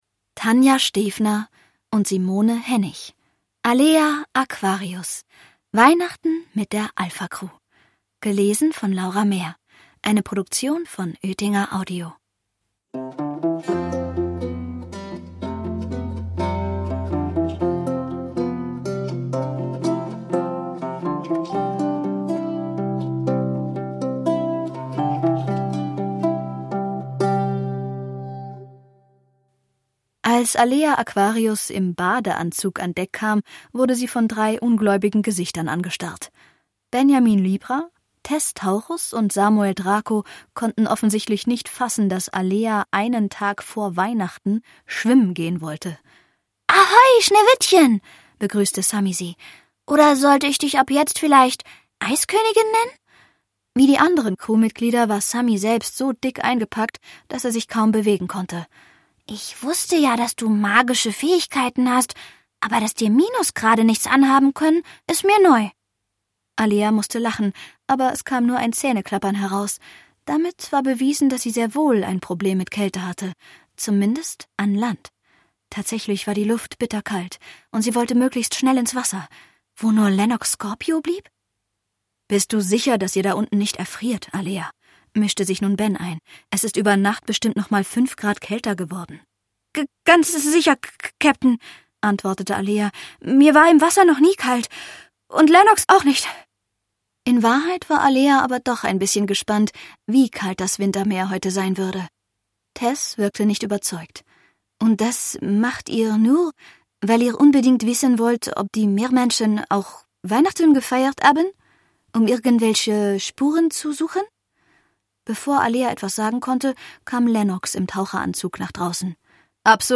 Ungekürzte Lesung, ca. 30 Minuten. Ihr erstes Weihnachten als Meermädchen will Alea nicht wie eine normale Landgängerin feiern. Gemeinsam mit Lennox sucht sie nach Weihnachtstraditionen unter Wasser und ruft dazu eine Finde-Finja.